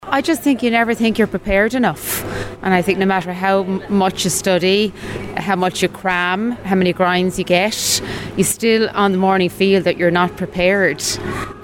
WLR went out to speak to people about their experiences and whether their results had any impact on their lives.
Another agreed with her and said that regardless of how much study you’ve done, you never feel prepared and ready until the paper is in front of you: